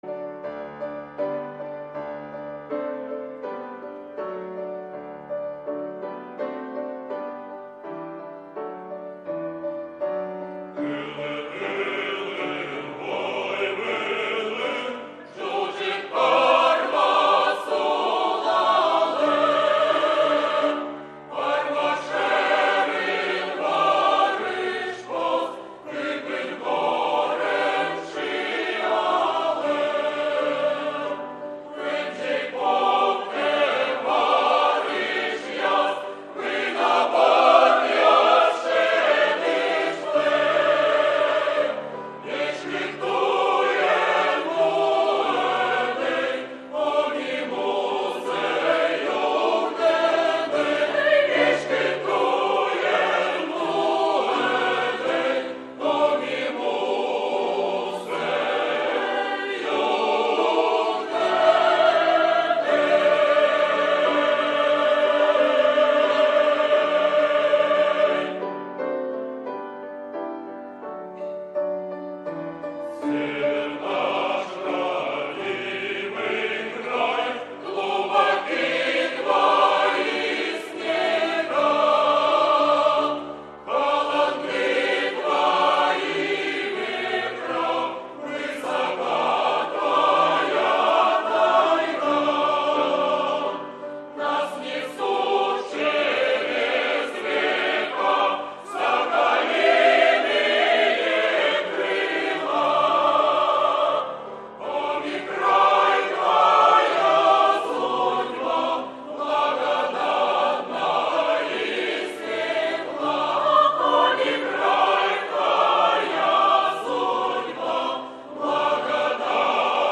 торжественное музыкальное произведение
со словами